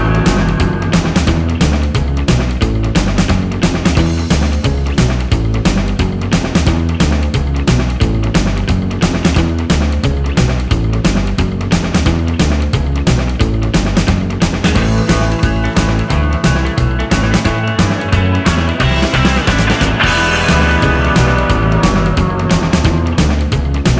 no Backing Vocals Rock 'n' Roll 3:01 Buy £1.50